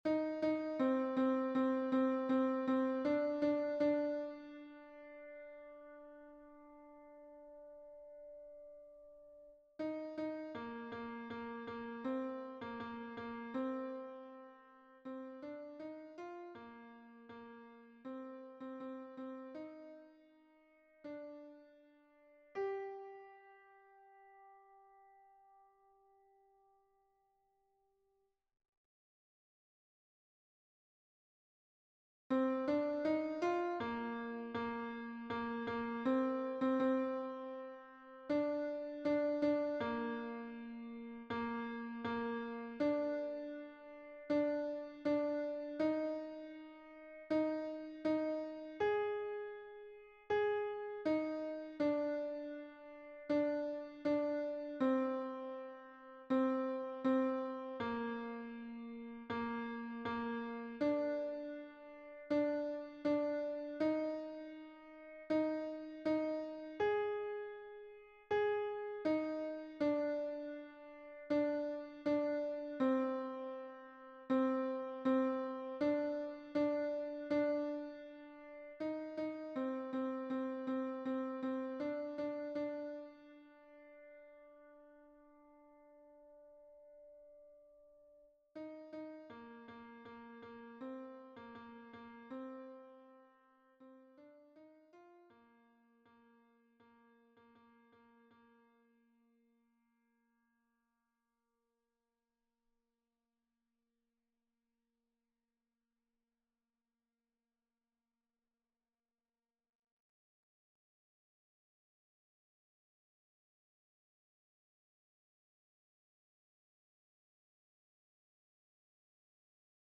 MP3 version piano
Alto 2